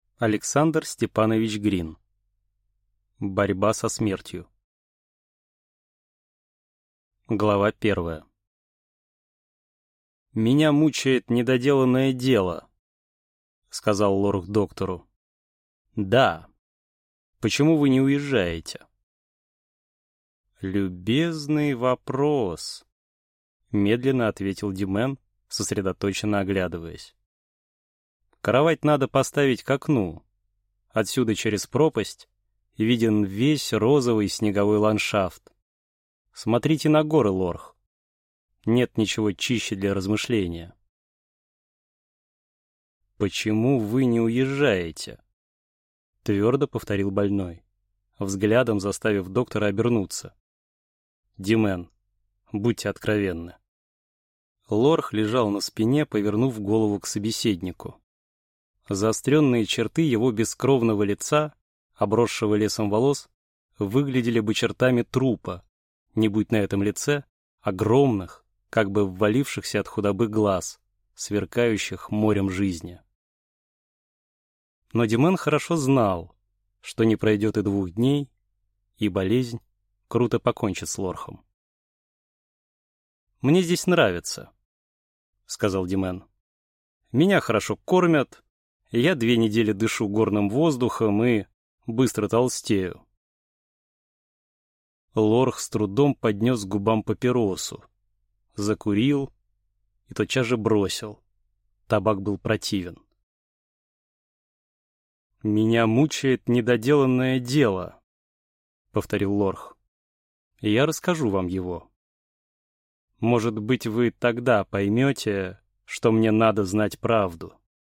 Аудиокнига Борьба со смертью | Библиотека аудиокниг